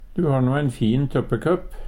tøppekøpp - Numedalsmål (en-US)